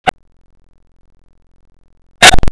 chickens crow somewhere. And dogs are barking.
chick01.wav